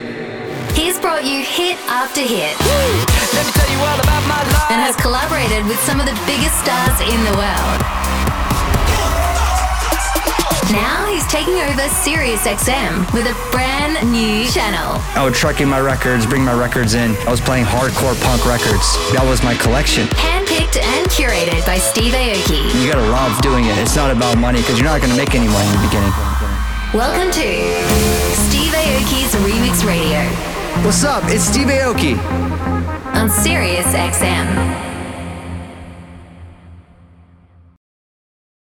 Imagens de rádio
Neumann U87
Soprano